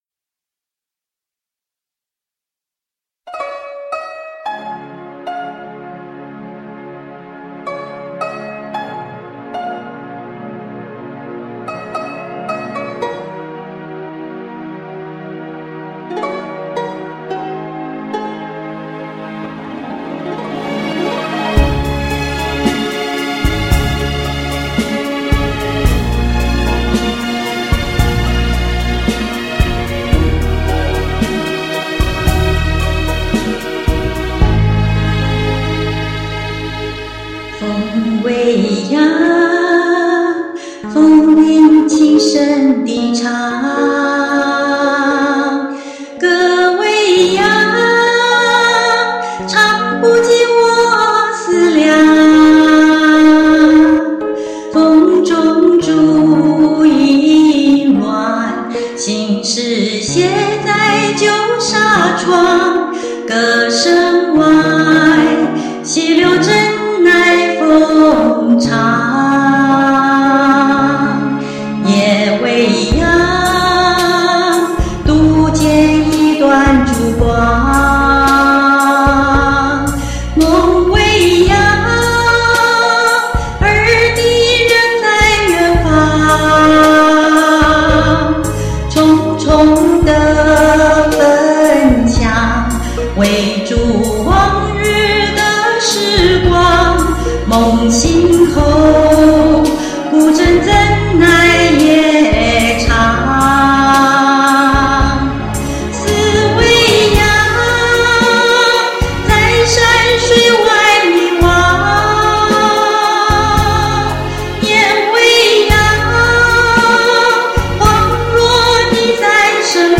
副歌特别好，高音真是轻扬飘逸。